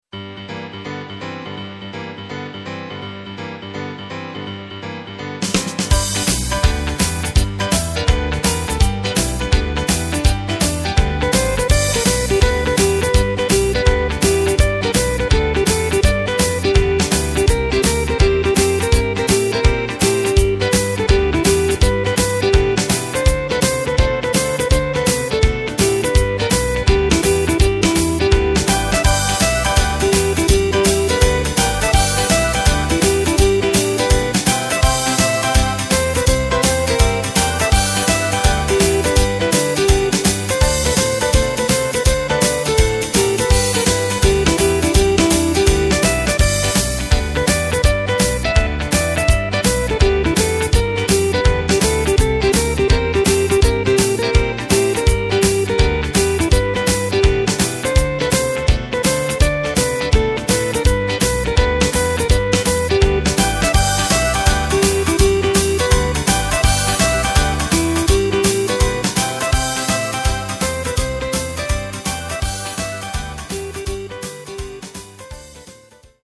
Takt:          4/4
Tempo:         166.00
Tonart:            G
Boogie aus dem Jahr 2016!